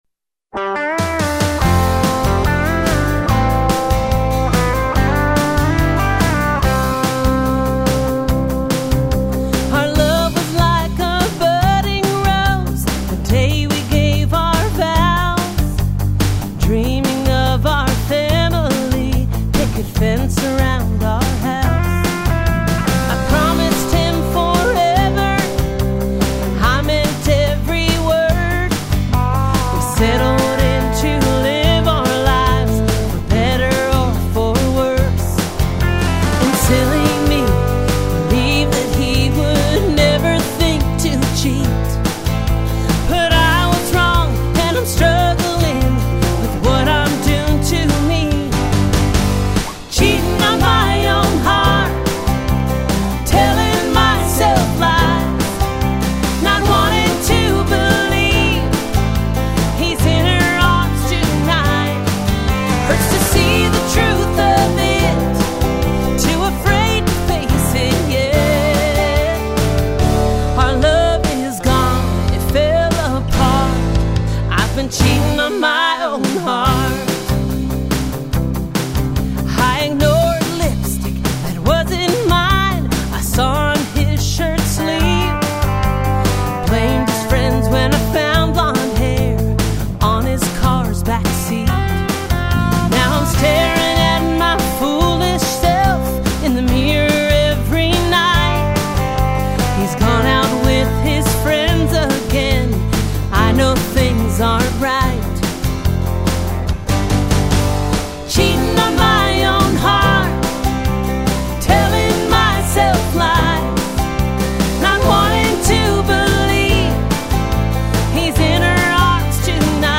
Complete Song
Complete Demo Song, with lyrics and music